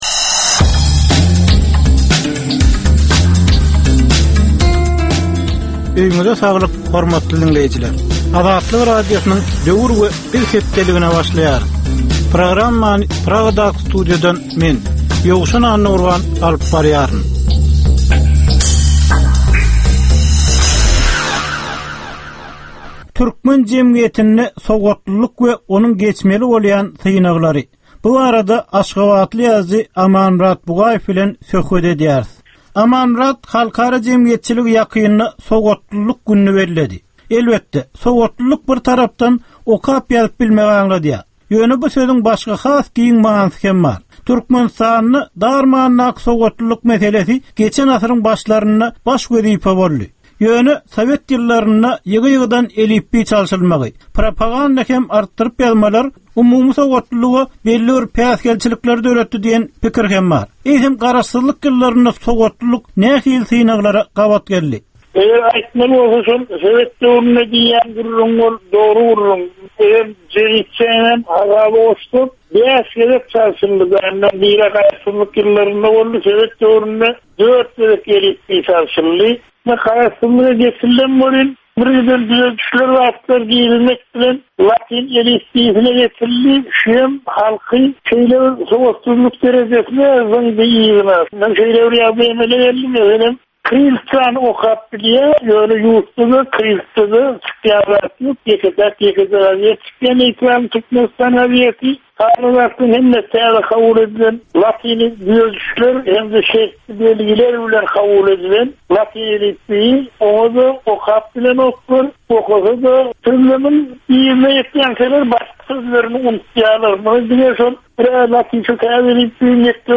Türkmen jemgyýetindäki döwrün meseleleri. Döwrün anyk bir meselesi barada 10 minutlyk ýörite syn-geplesik. Bu geplesikde dinleýjiler, synçylar we bilermenler döwrün anyk bir meselesi barada pikir öwürýärler, öz garaýyslaryny we tekliplerini orta atýarlar.